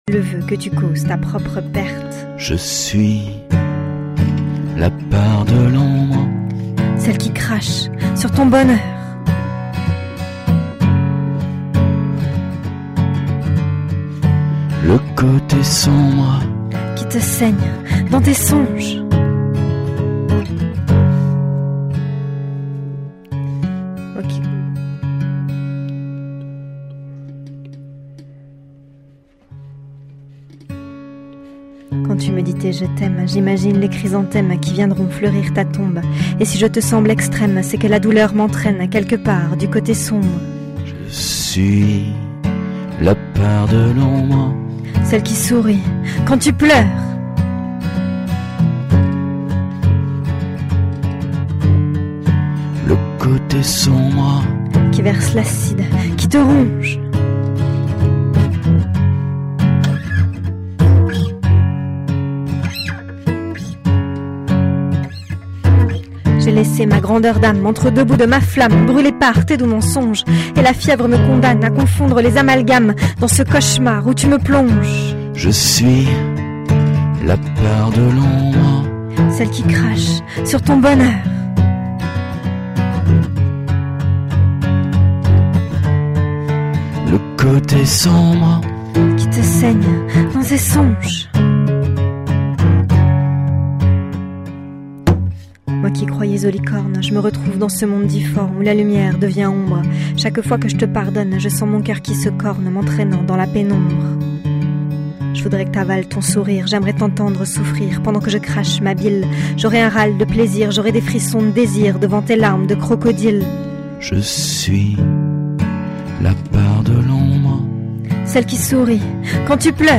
Genre: Talk Show